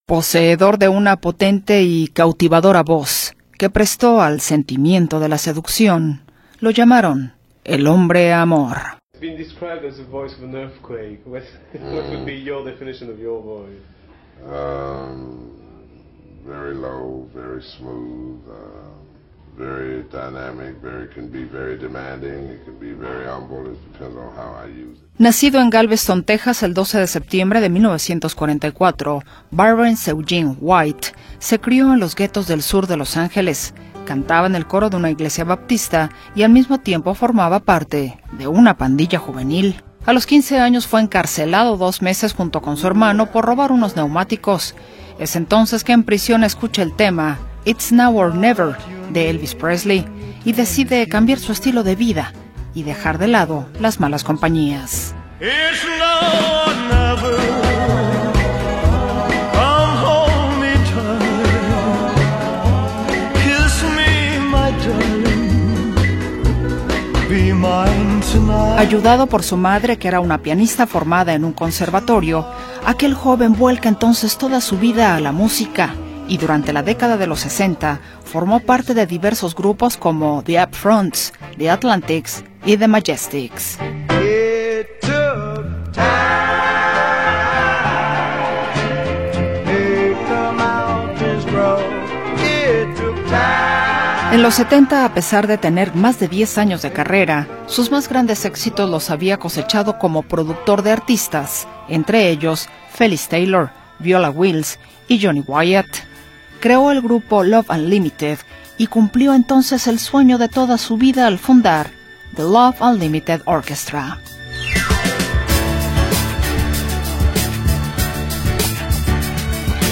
Barrence Eugene White Carter, mejor conocido artísticamente como Barry White, fue un compositor, cantante, arreglista y productor musical estadounidense de los géneros disco, R&B y soul. Caracterizado por un timbre bajo, grave y ronco, ganó dos premios Grammy en el 2000 y otros reconocimientos, como los premios Soul Train o los American Music.